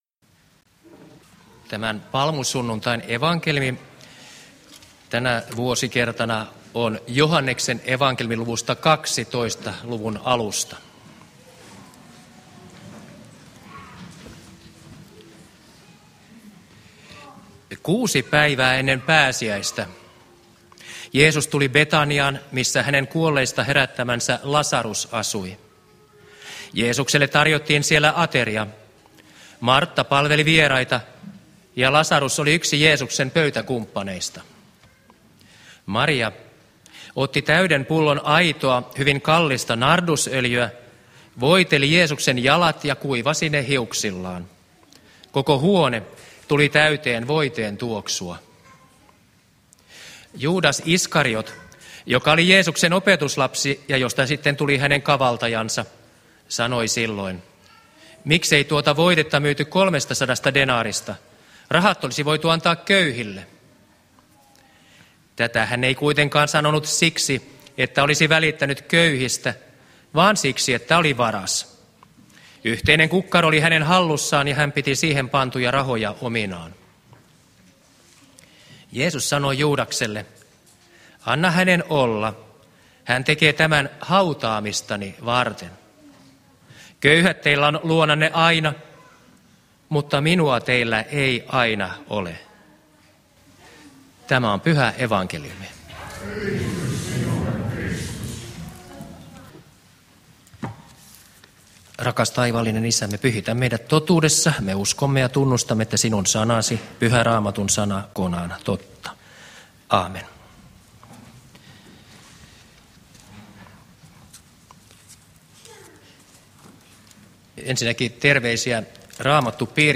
saarna Turussa palmusunnuntaina Tekstinä Joh. 12: 1-8